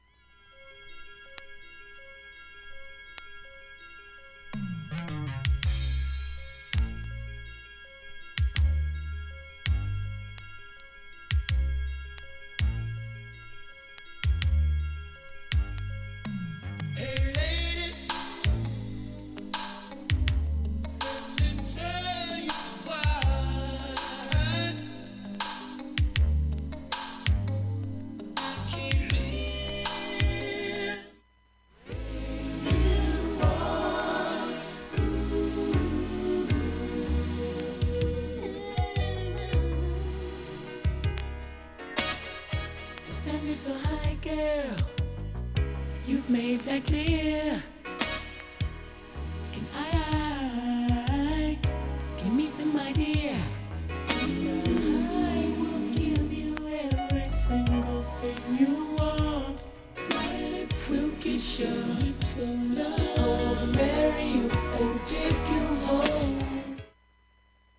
盤面きれいで音質良好全曲試聴済み。 瑕疵部分 A-1始めにかすかなプツが4回と９回出ます。
単発のかすかなプツが2箇所
人気MELLOW CLASSIC ヴォコーダー